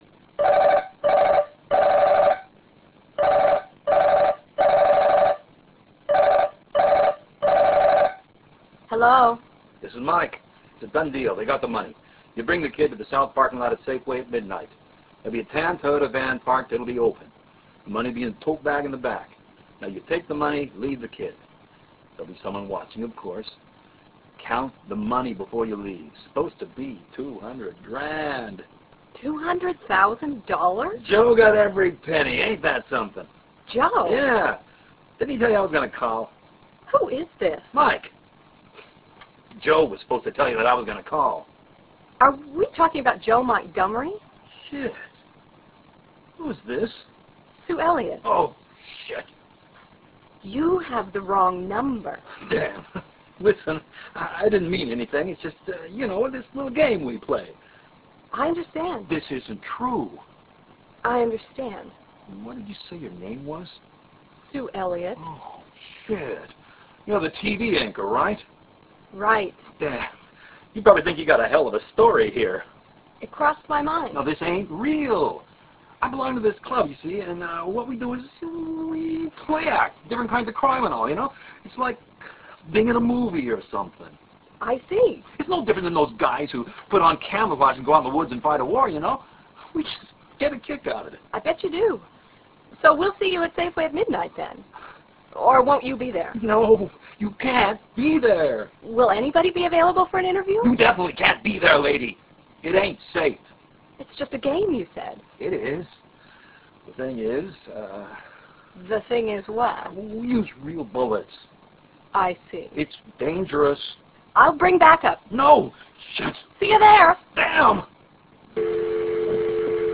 Very short plays on the phone